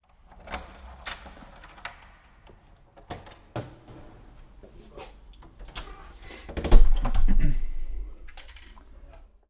So gut klingt 3D-Sound mit dem Sennheiser Ambeo Smart Headset
Tür
Alle Geräusche sind erstaunlich klar zu lokalisieren.
Bewegungen einer Schallquelle im Raum wirken viel greifbarer.
sennheiser_ambeo_smart_headset_test__tuer.mp3